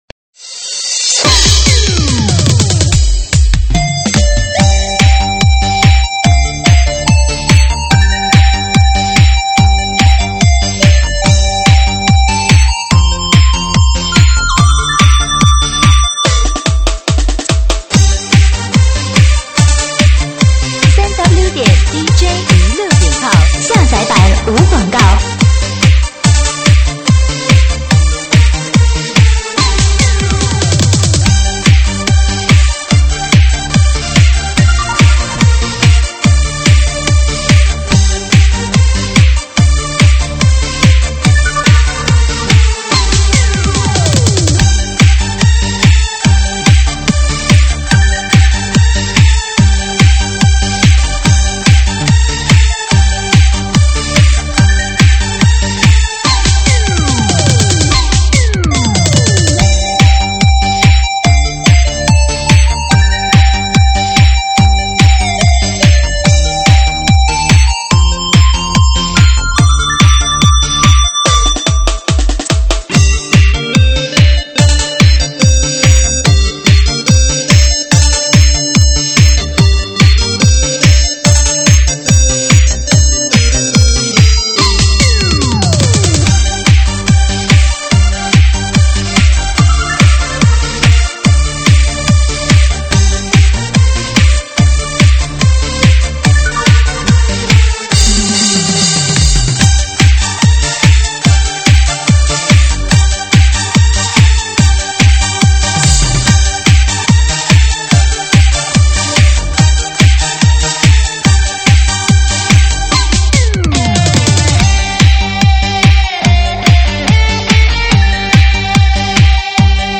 [慢摇舞曲]
舞曲类别：慢摇舞曲